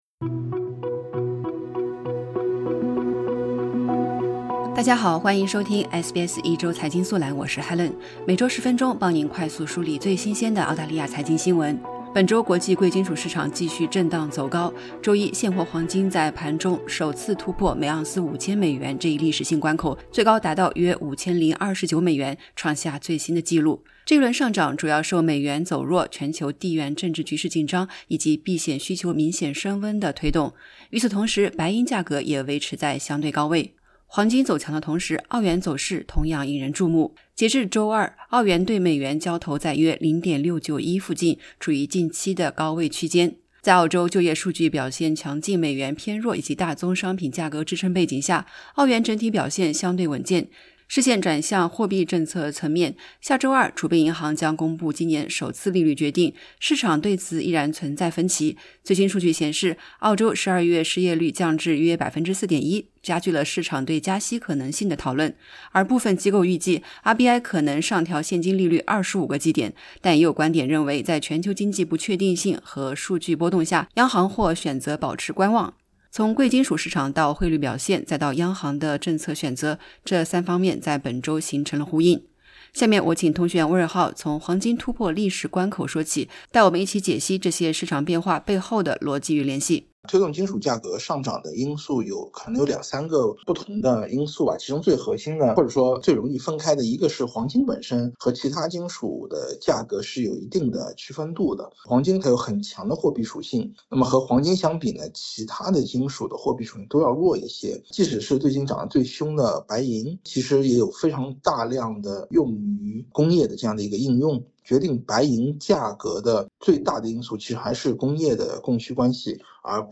点击播放键收听完整采访 国际金价突破5000美元/盎司 RBA下周作出利率决定 SBS Chinese 07:46 Chinese 点击此处，了解更多关于SBS如何应用人工智能（AI）的信息。